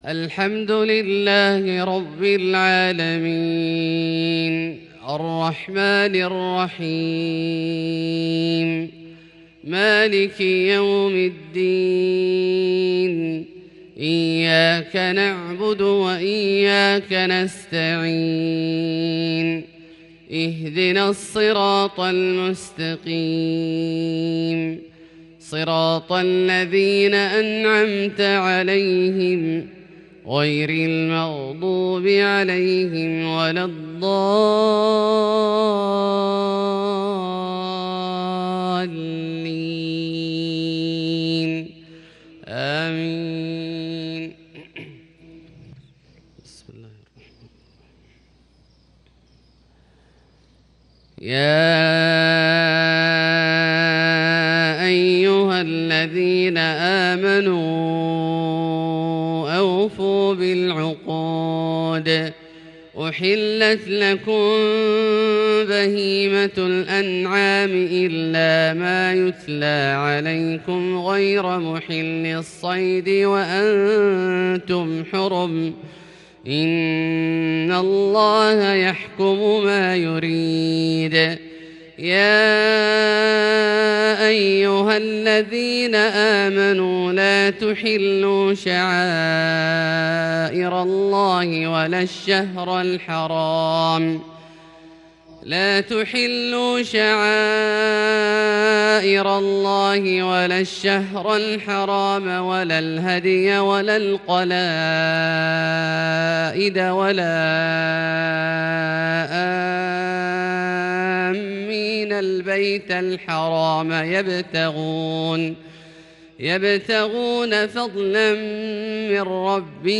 صلاة الفجر 8-3-1442 تلاوة من سورة المائدة (١-١١) > ١٤٤٢ هـ > الفروض - تلاوات عبدالله الجهني